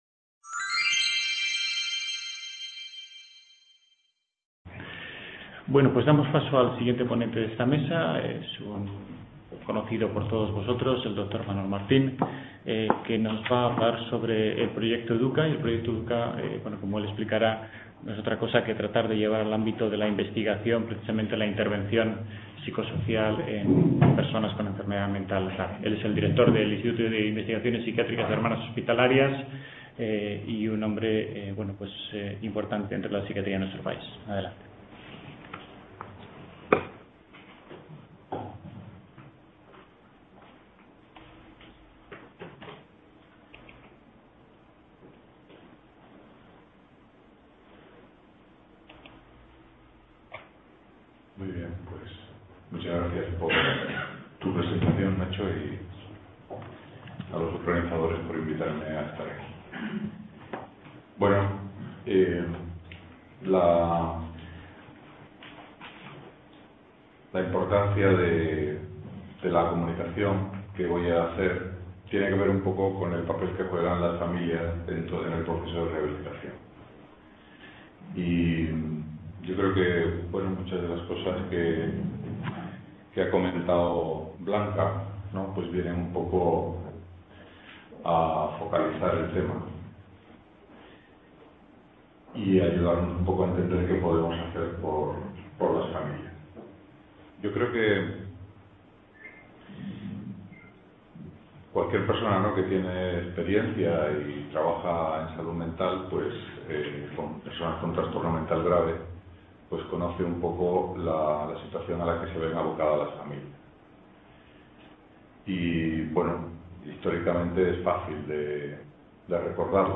XV Reunión Anual de la Sociedad Vasco Navarra de Psiquiatría